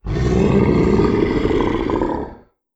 Monster Roars
09. Mythic Roar.wav